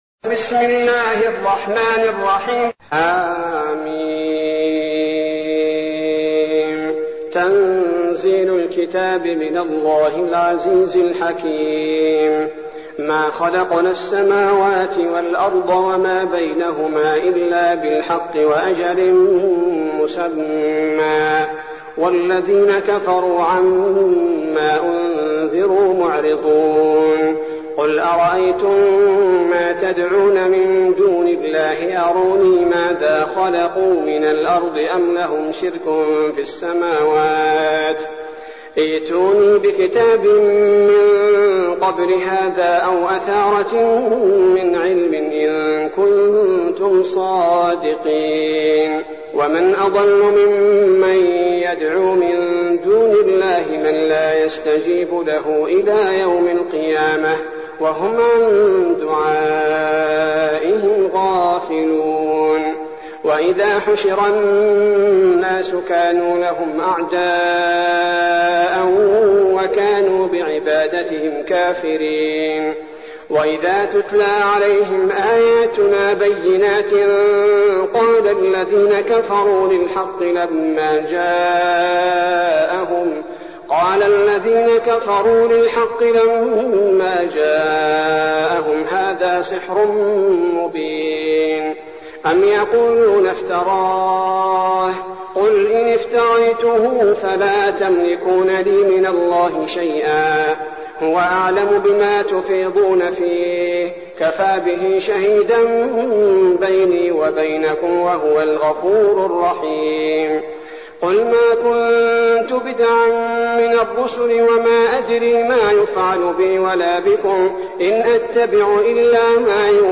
المكان: المسجد النبوي الشيخ: فضيلة الشيخ عبدالباري الثبيتي فضيلة الشيخ عبدالباري الثبيتي الأحقاف